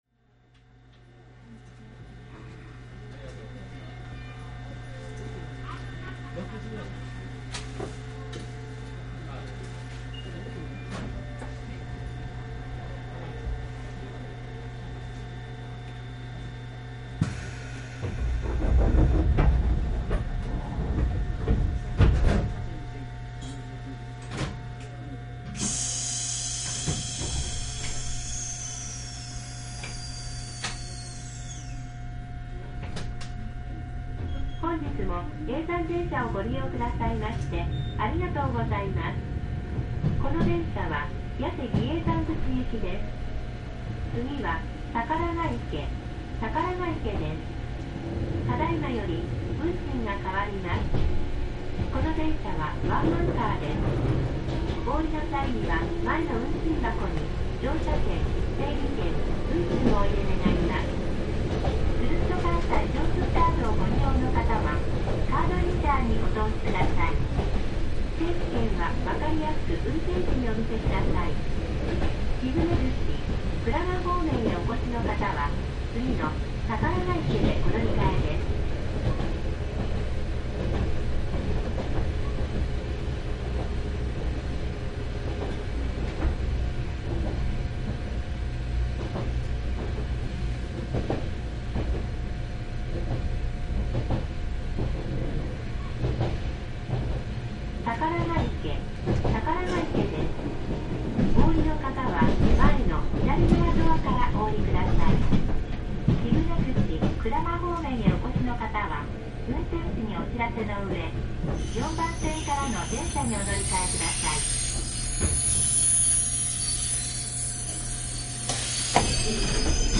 修学院前～八瀬比叡山口　走行音（６分２４秒）